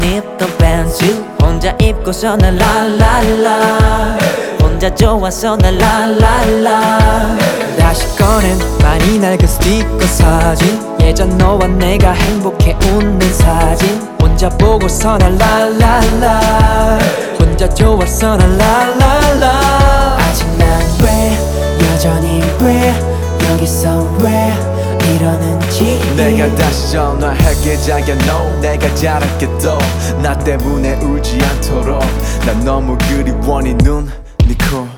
K-Pop Pop
2014-01-13 Жанр: Поп музыка Длительность